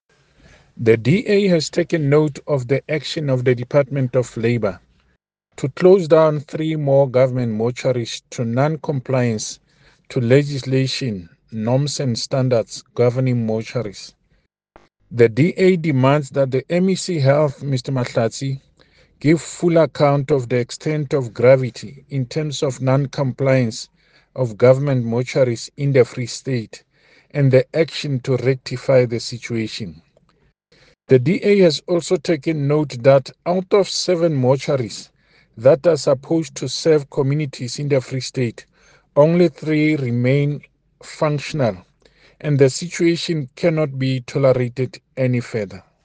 Sesotho soundbites by David Masoeu MPL